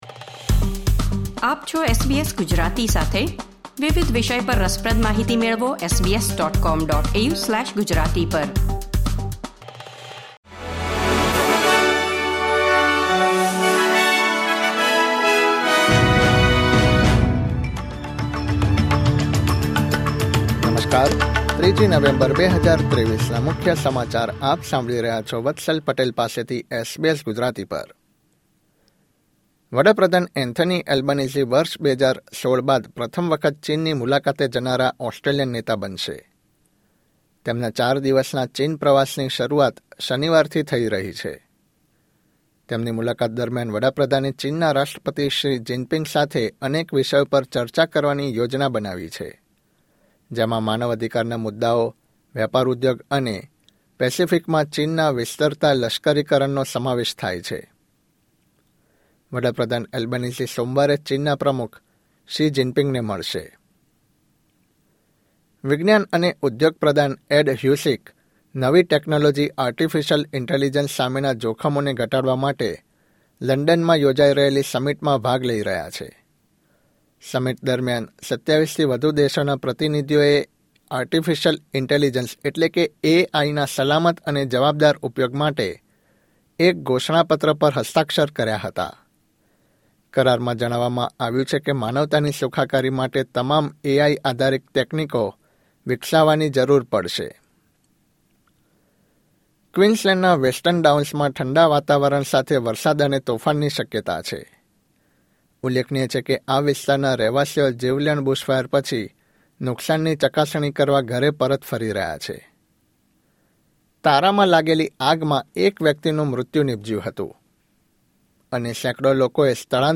SBS Gujarati News Bulletin 3 November 2023